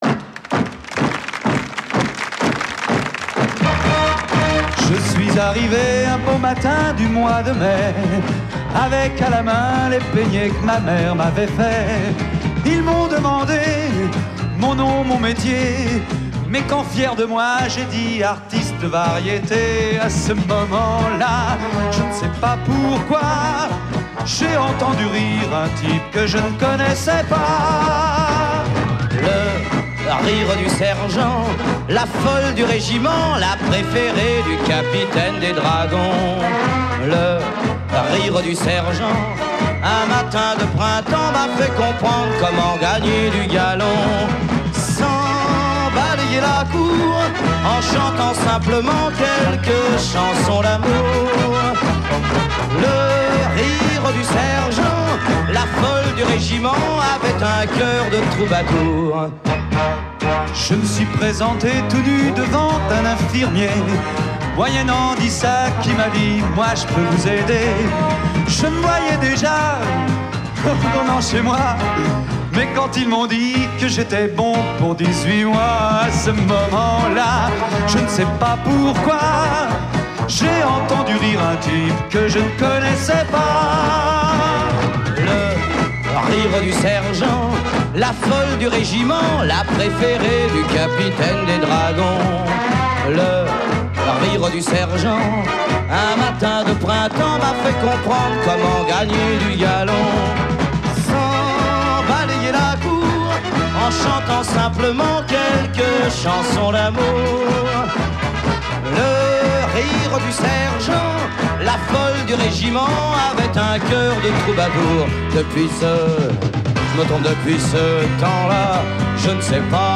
Versions TV